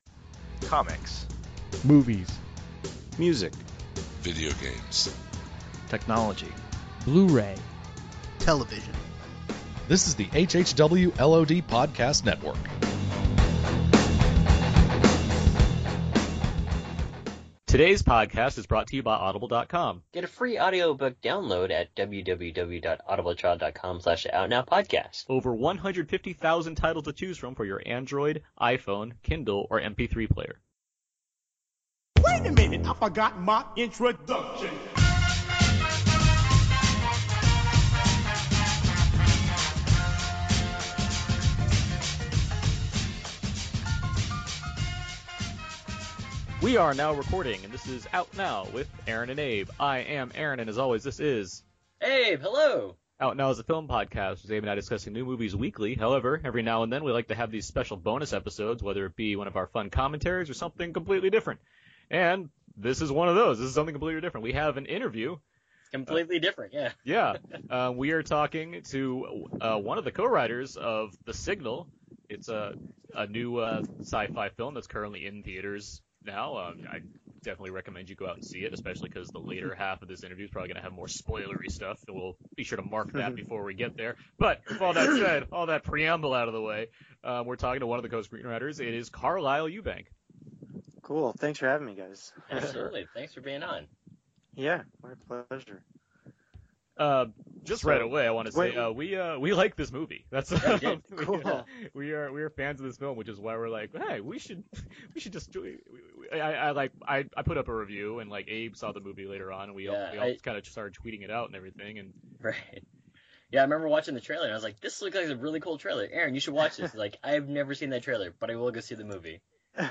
If you haven't seen "The Signal" yet, then you should listen to this Q&A session (before we hit some spoilers) then go and check out the film at a theater near you! We discuss the concepts of the film, production of the film, being accepted into film festivals, and so much more!